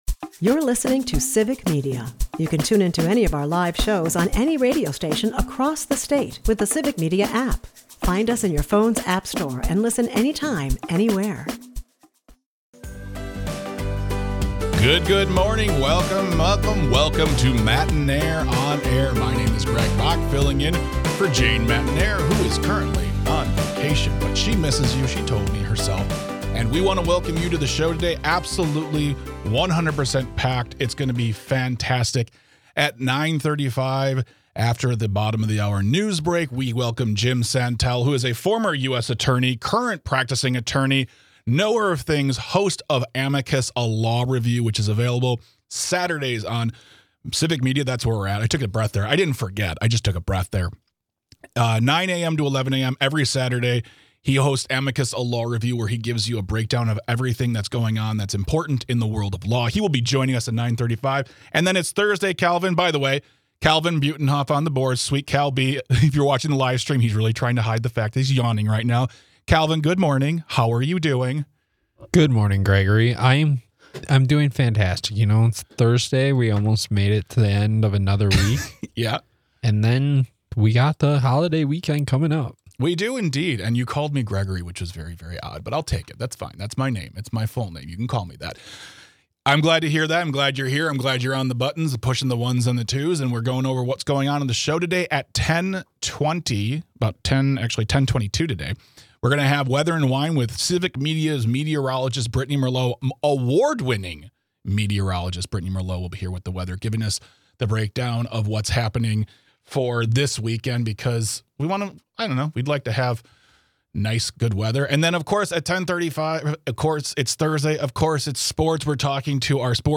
Then, we welcome Jim Santelle to the show to break down everything going on in the world of law. He gives an expert opinion on how gun control can be achieved without infringing on our rights and he also has an update on Judge Hannah Dugan, who is being targeted by The Trump Administration .
Matenaer On Air is a part of the Civic Media radio network and airs weekday mornings from 9-11 across the state.